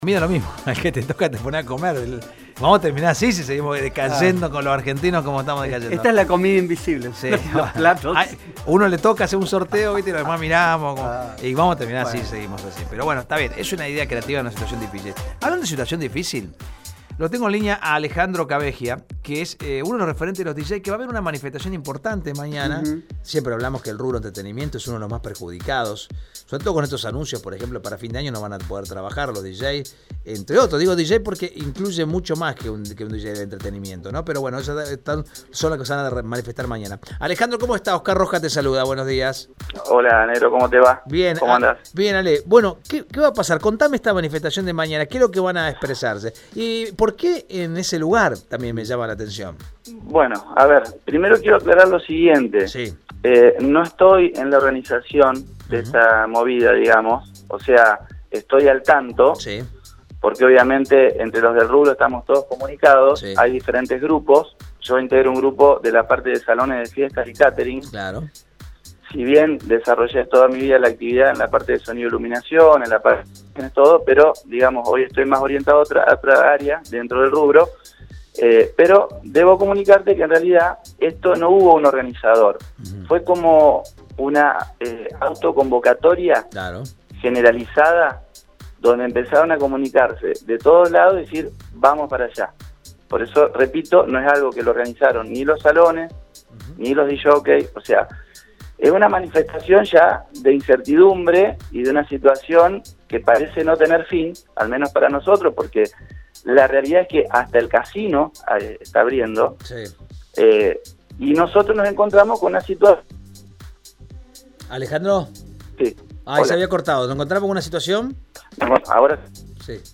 En dialogo con Radio EME